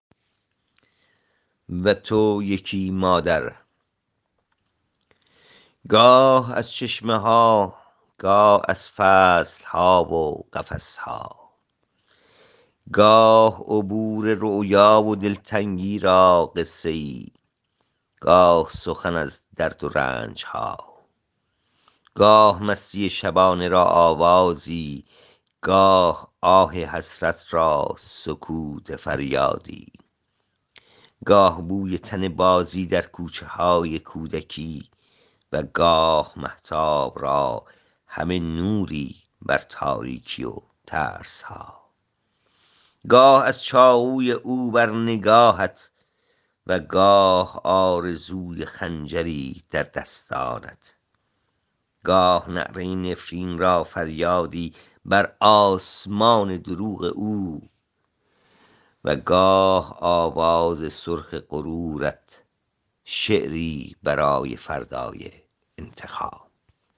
این شعر را با صدای شاعر از این جا بشنوید